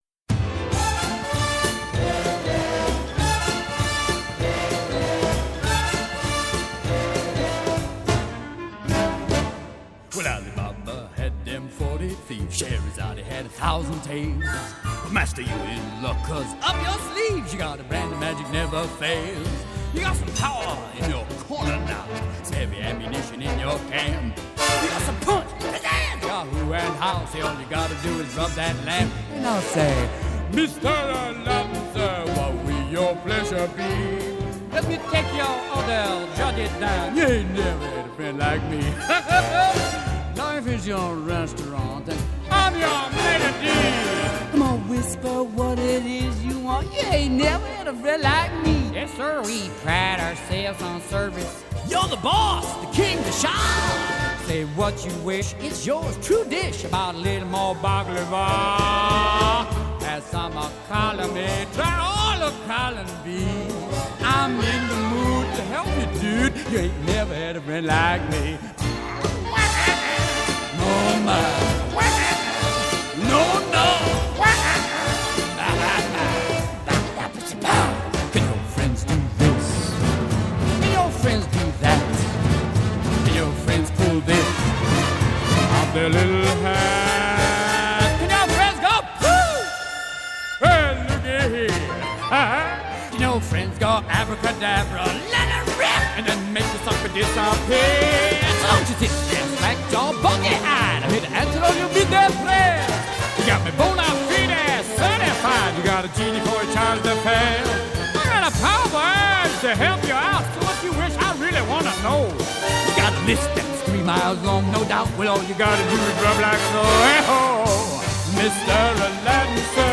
Pop, Soundtrack